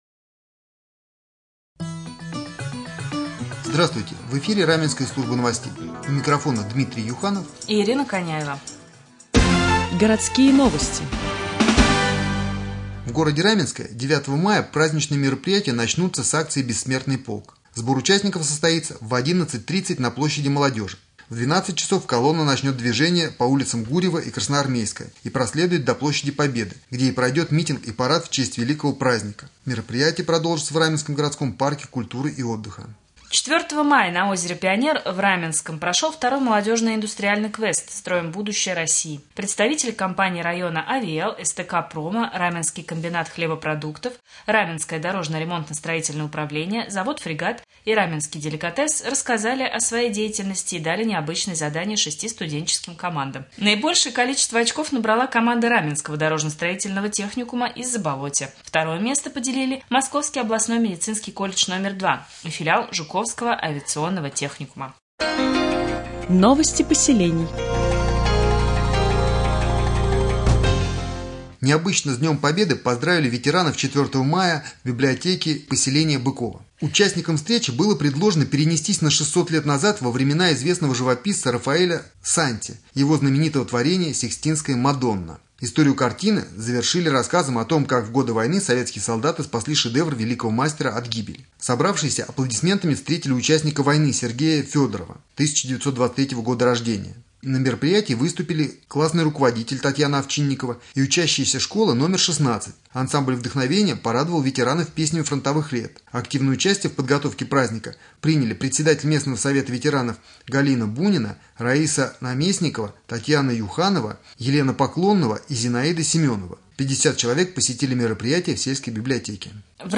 1.-Novosti.mp3